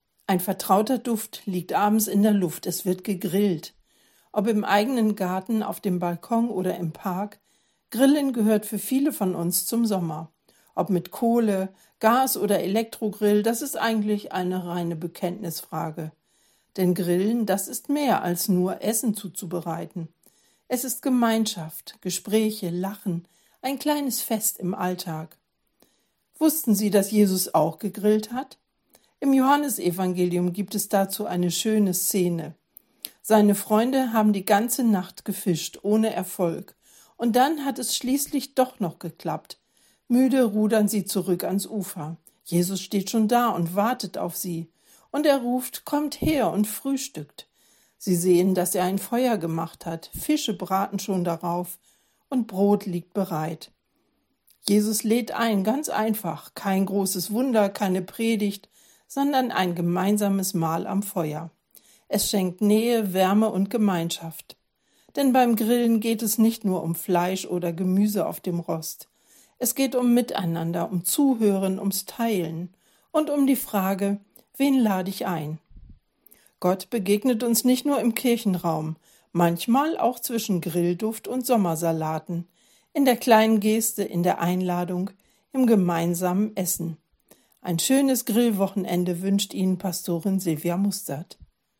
Radioandacht vom 25.07.